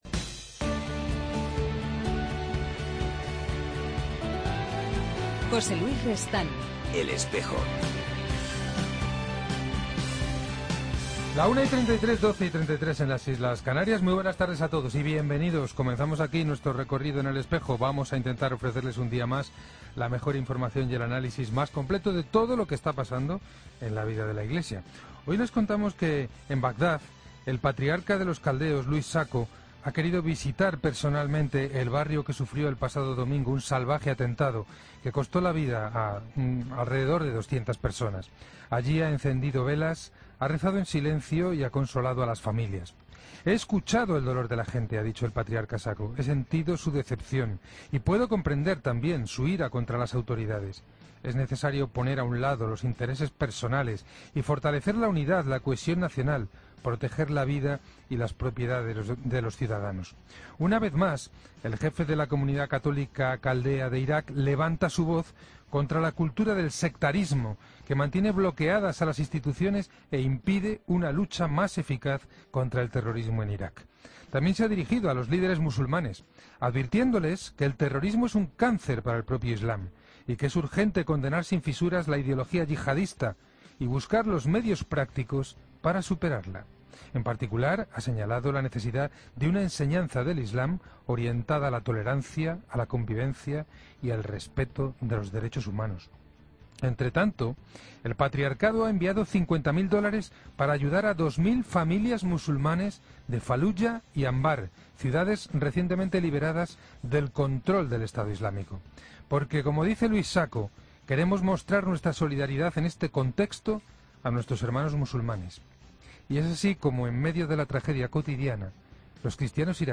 AUDIO: Hablamos con Monseñor Miguel Ángel Ayuso de la Semana de Misionología que se celebra en Burgos.